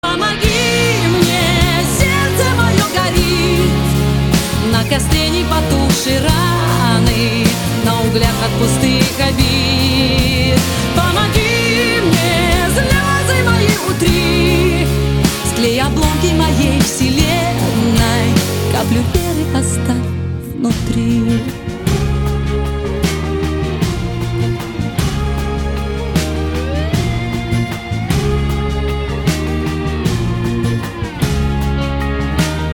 • Качество: 160, Stereo
красивые
женский вокал
Pop Rock